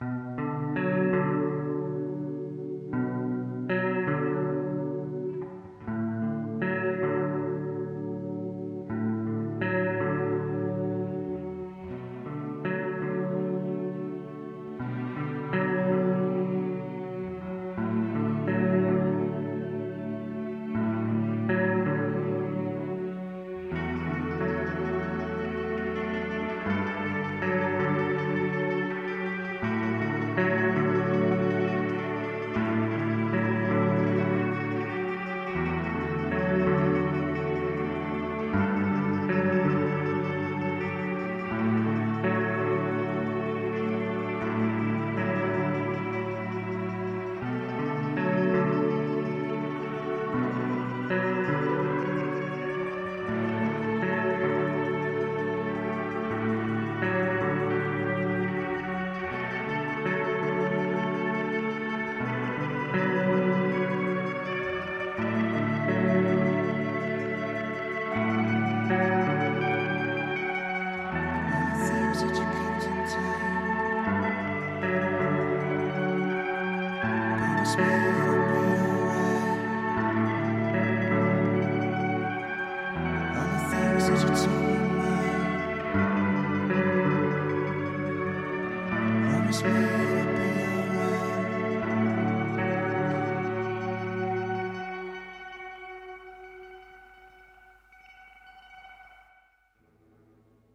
guitar
using a looper pedal
Hauntingly beautiful